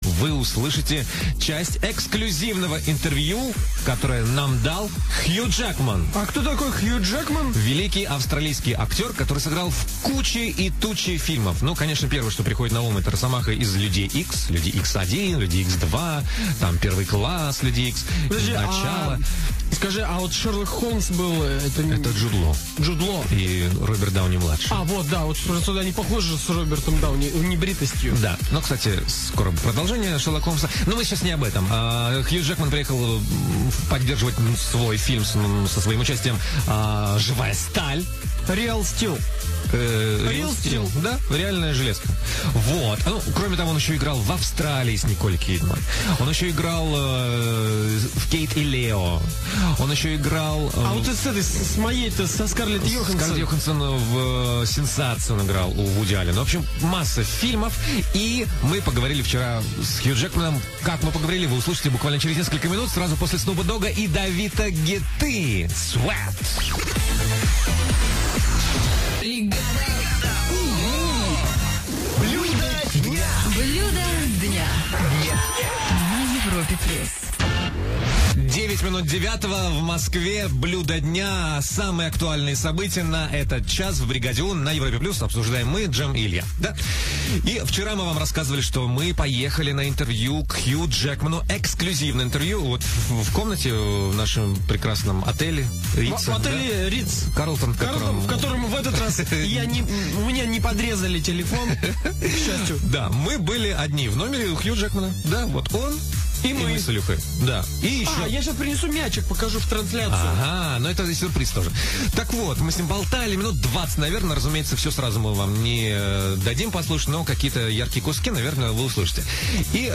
Утреннее шоу на "Европе Плюс" "Бригада У", эксклюзивное интервью с Хью Джекманом.